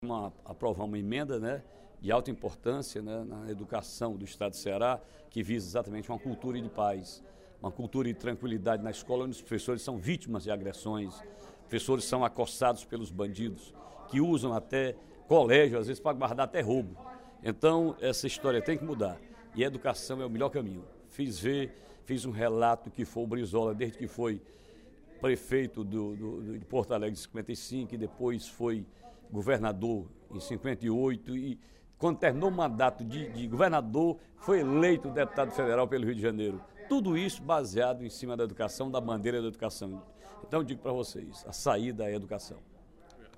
O deputado Ferreira Aragão (PDT) comemorou, durante o primeiro expediente da sessão plenária desta quarta-feira (26/04), a aprovação, pela Comissão de Educação da Casa, do projeto de lei nº 24/17, oriundo da mensagem nº 8.112/17, do Poder Executivo.